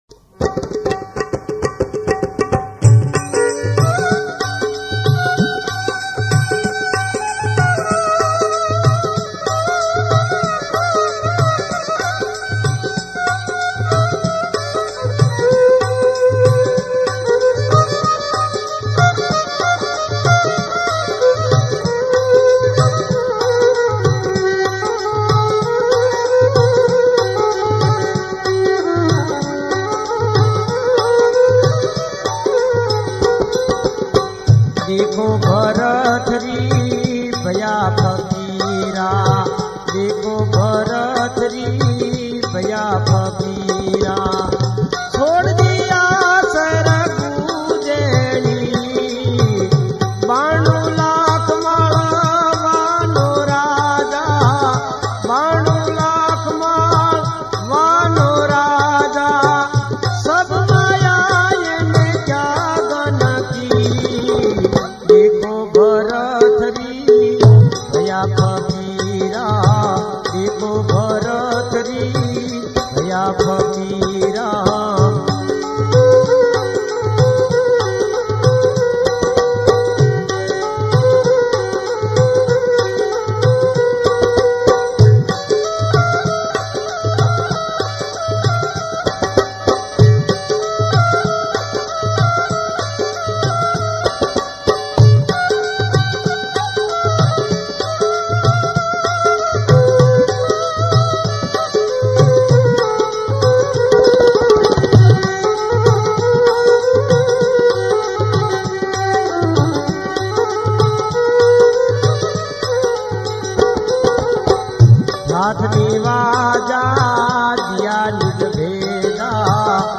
Gujarati Garba